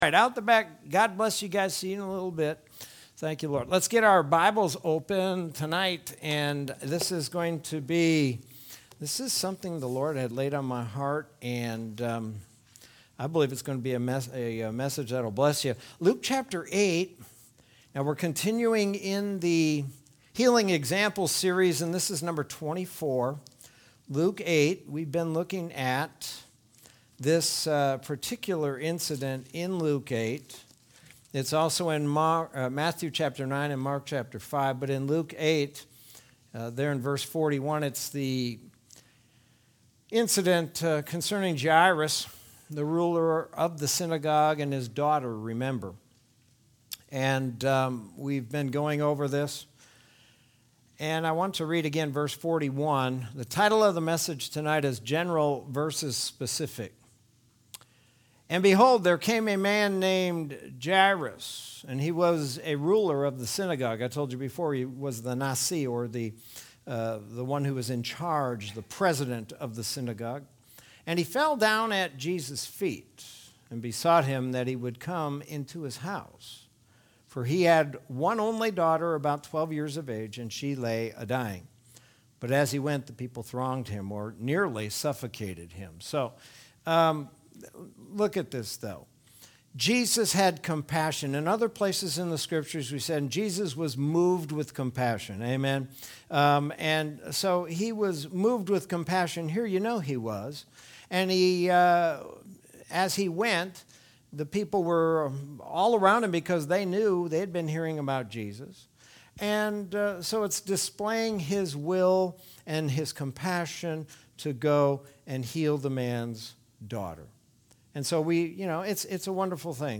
Sermon from Wednesday, August 4th, 2021.